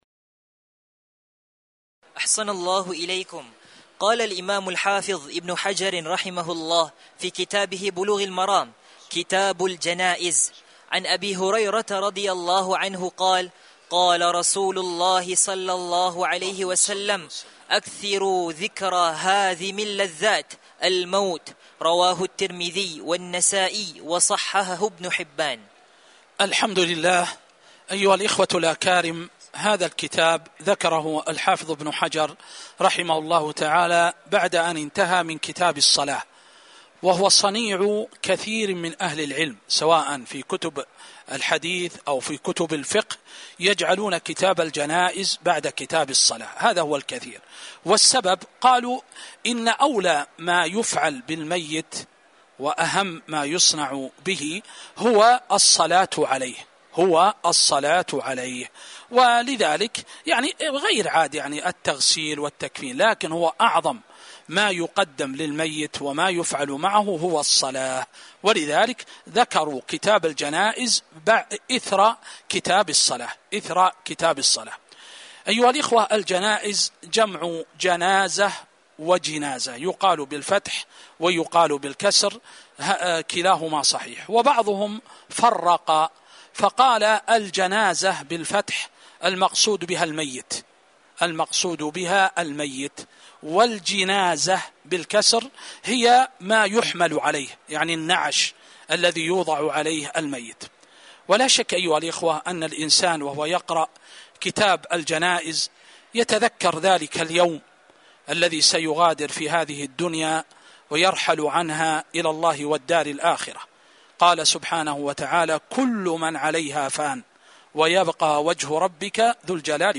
تاريخ النشر ١٠ شعبان ١٤٤٥ هـ المكان: المسجد النبوي الشيخ